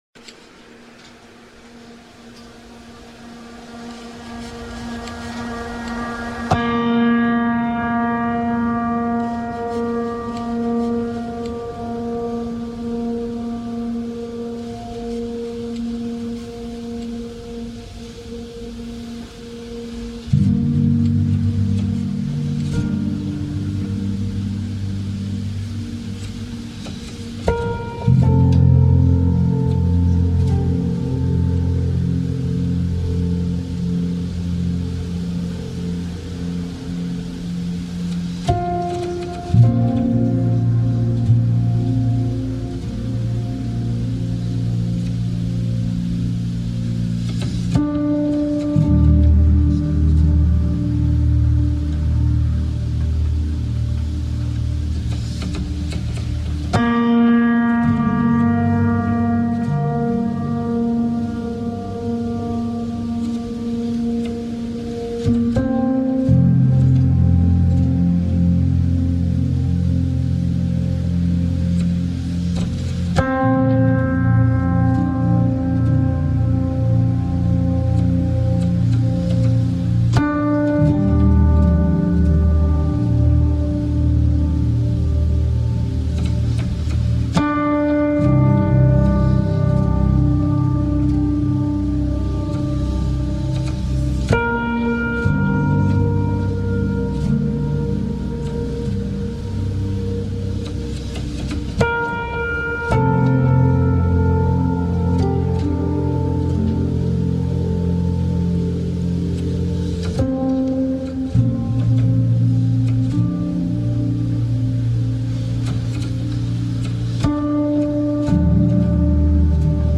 Sound Healing